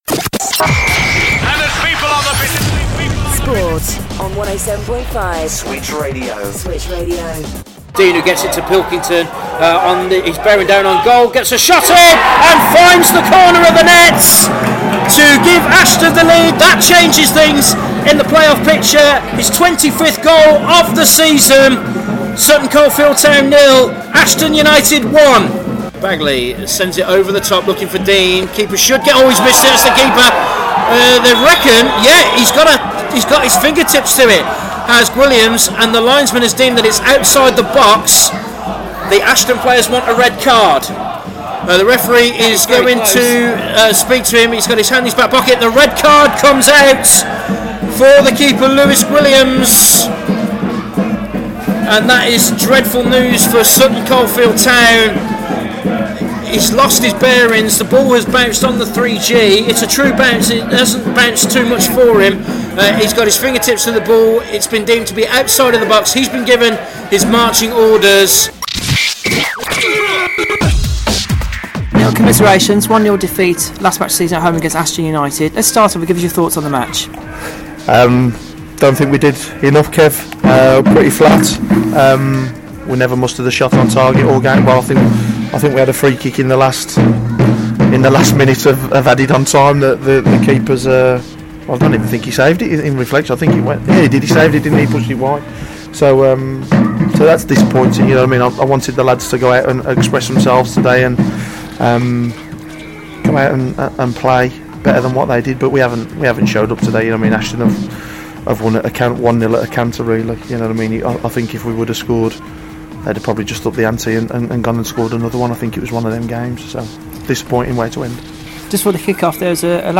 Highlights and post match interview